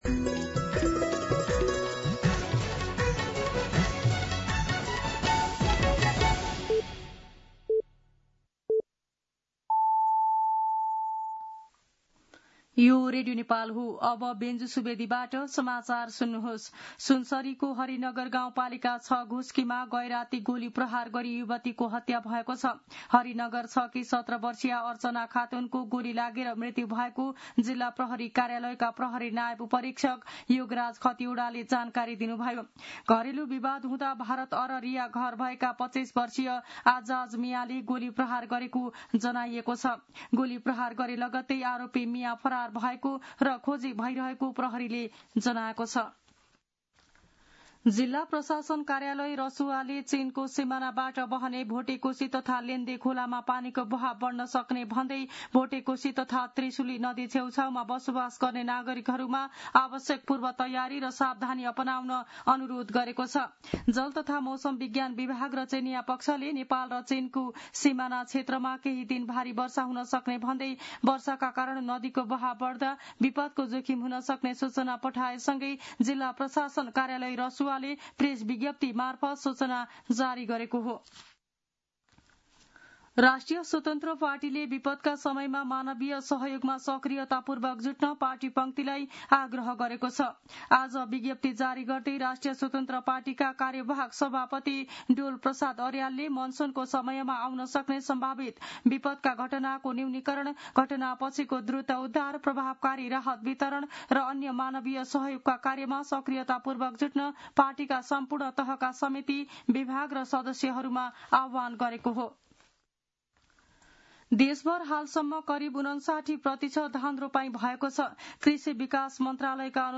मध्यान्ह १२ बजेको नेपाली समाचार : १८ पुष , २०२६